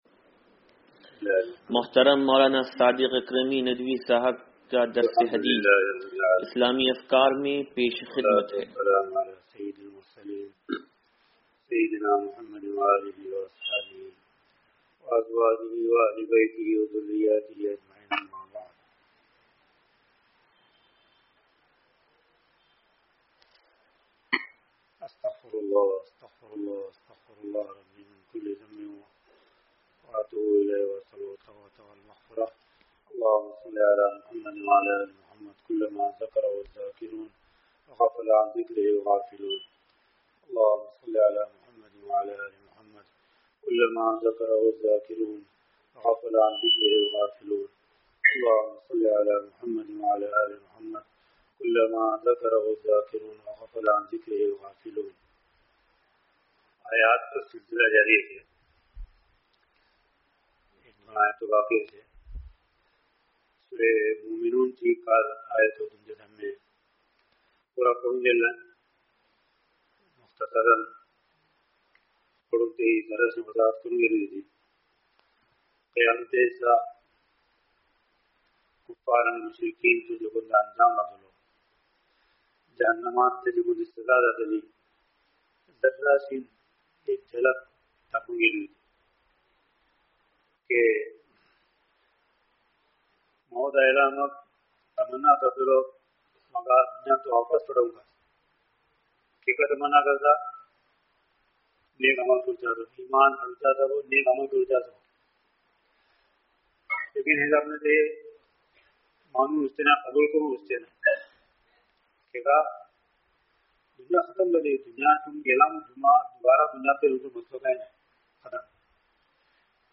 درس حدیث نمبر 0573